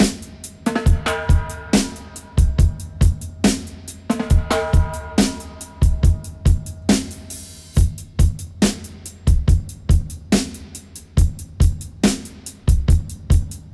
141-FX-06.wav